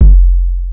SouthSide Kick Edited (57).wav